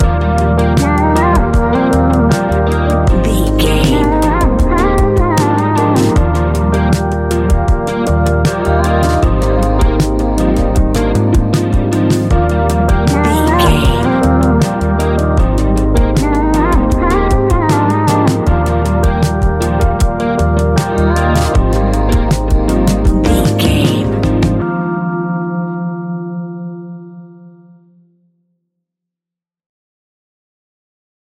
Ionian/Major
D♯
laid back
Lounge
sparse
new age
chilled electronica
ambient
atmospheric
instrumentals